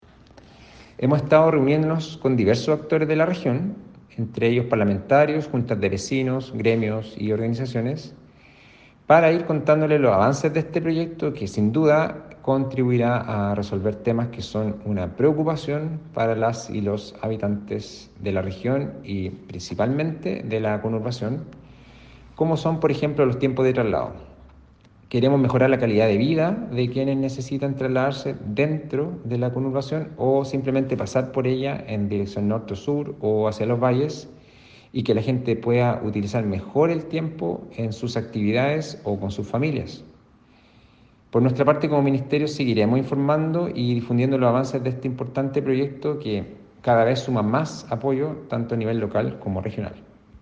Ante esto, el SEREMI MOP Javier Sandoval, señaló que
SEREMI-MOP-Javier-Sandoval.mp3